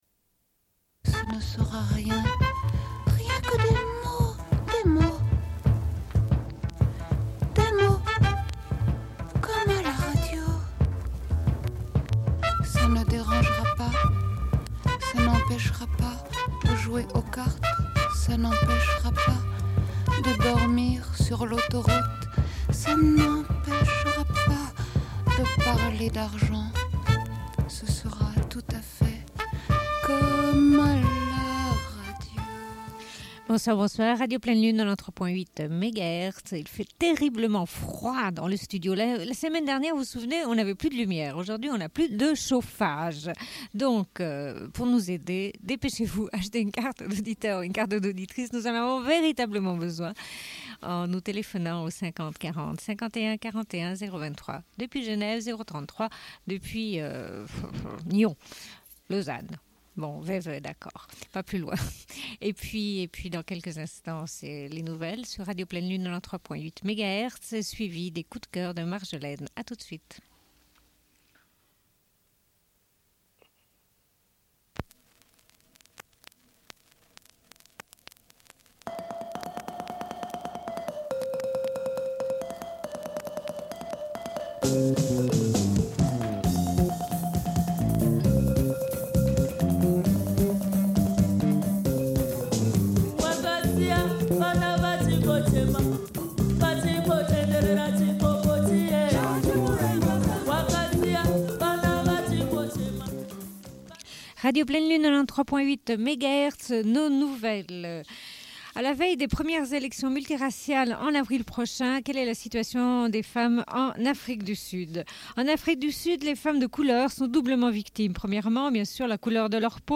Bulletin d'information de Radio Pleine Lune du 19.01.1994 - Archives contestataires
Une cassette audio, face B30:14